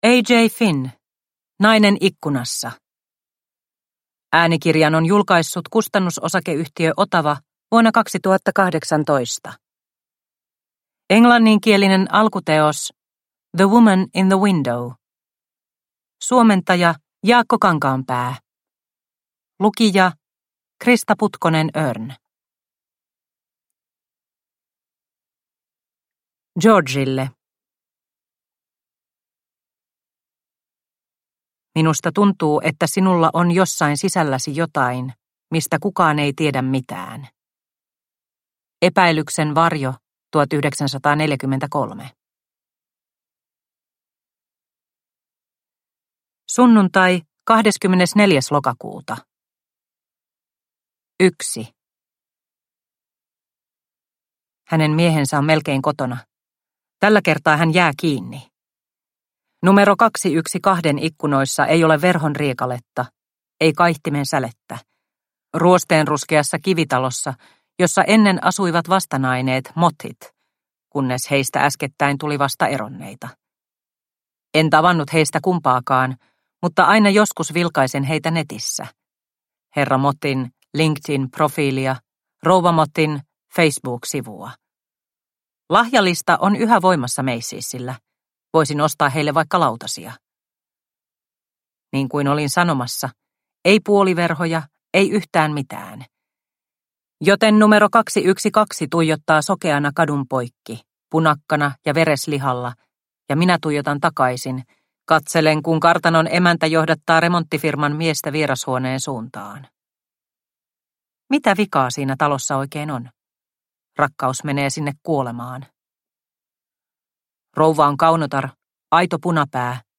Nainen ikkunassa – Ljudbok – Laddas ner